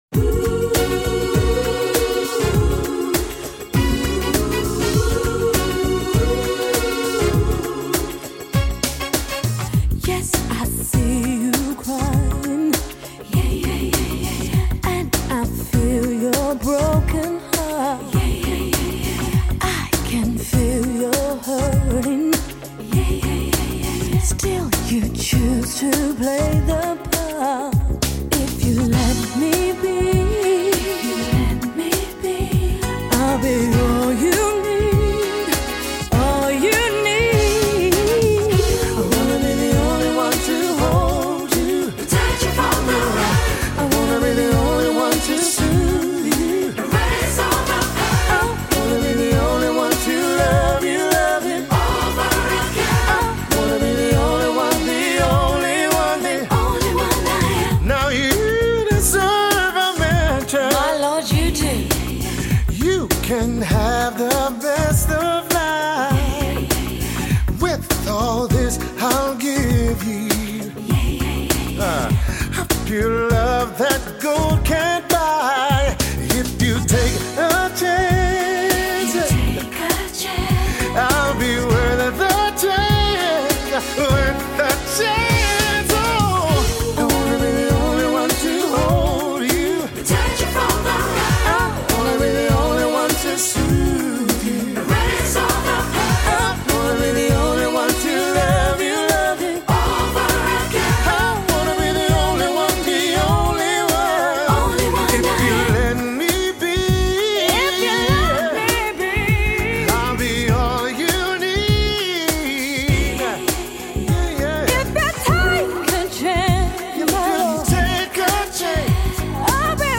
R&B
un grupo de blues británico